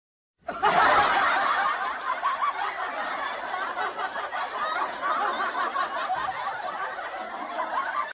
Download Laughing Choir sound button
laughing-choir.mp3